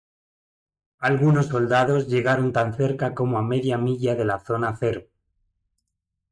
Pronounced as (IPA) /ˈmiʝa/